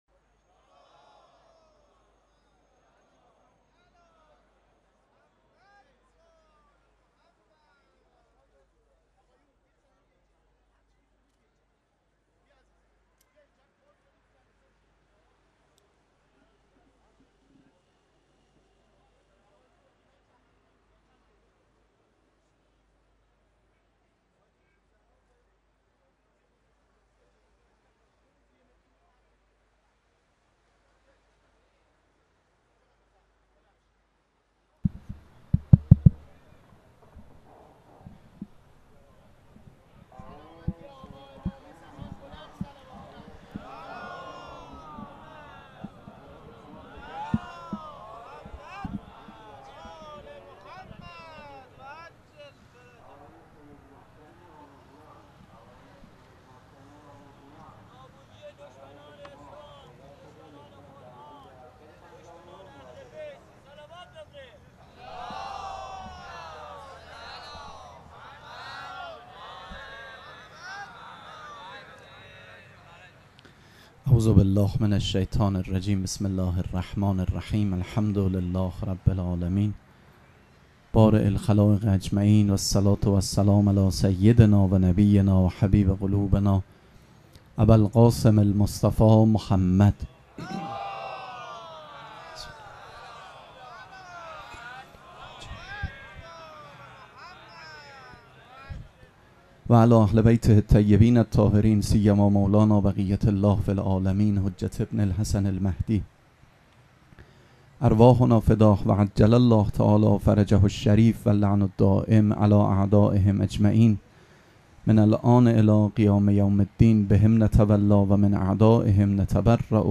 سخنرانی
شب پنجم مراسم عزاداری اربعین حسینی ۱۴۴۷ سه‌شنبه ۲۱ مرداد ۱۴۰۴ | ۱۸ صفر ۱۴۴۷ موکب ریحانه الحسین سلام الله علیها